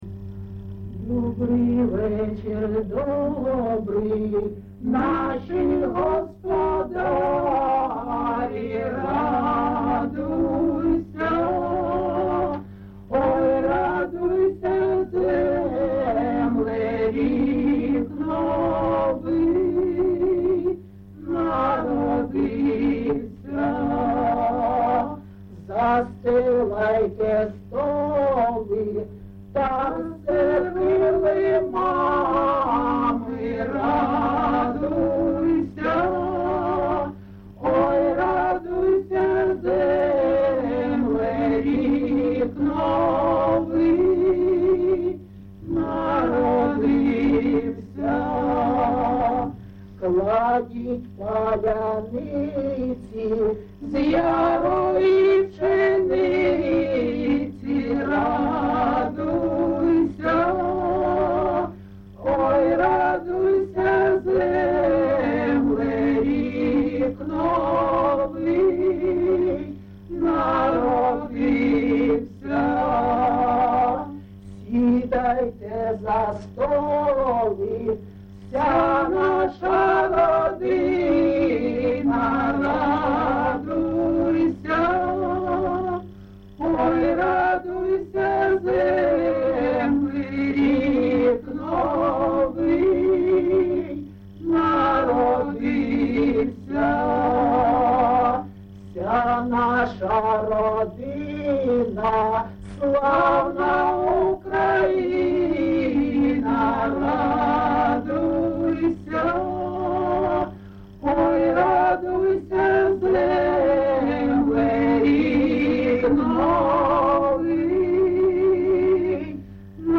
ЖанрКолядки, Сучасні пісні та новотвори
Місце записум. Костянтинівка, Краматорський район, Донецька обл., Україна, Слобожанщина